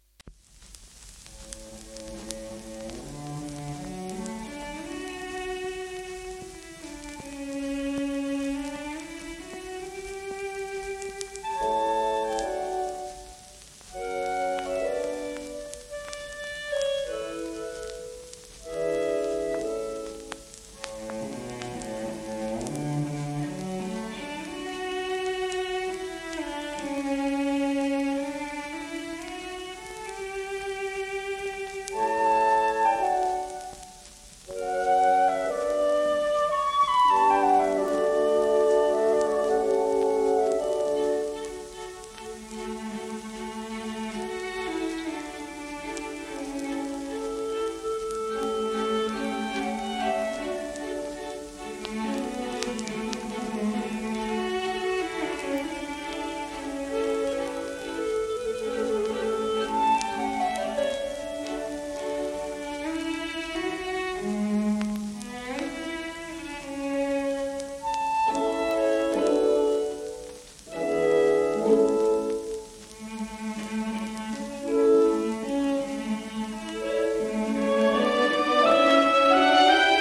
1938年頃録音